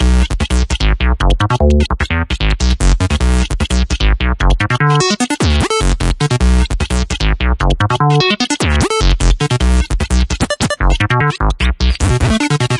电贝司合成音乐低音循环
描述：电贝司合成音乐低音循环。电贝司，带滤波器的合成器
Tag: 低音的 电子 合成器 技术 循环